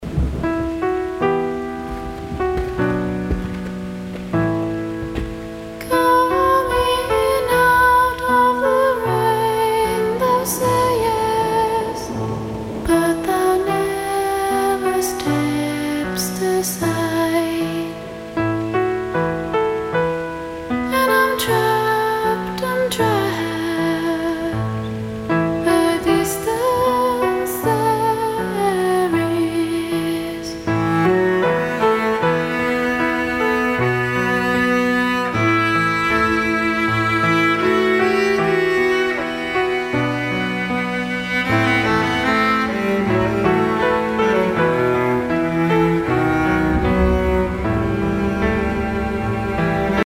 Музыка » Rock » Rock